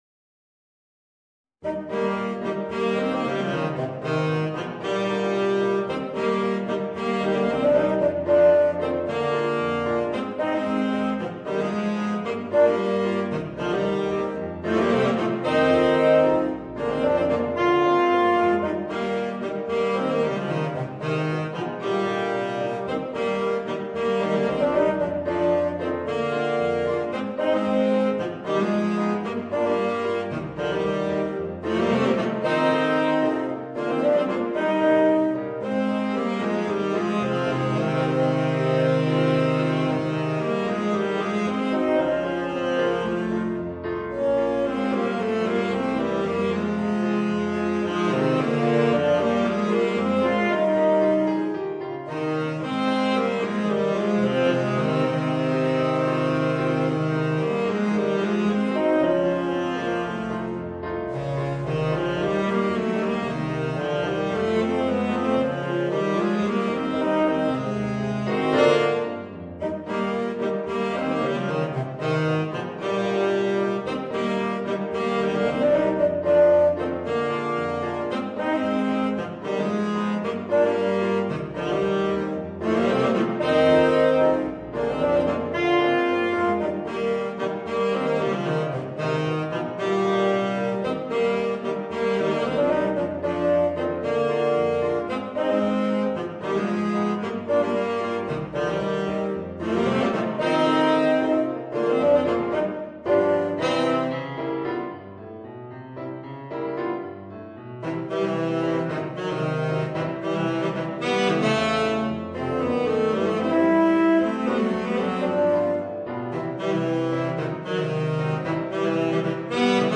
Voicing: 2 Tenor Saxophones and Piano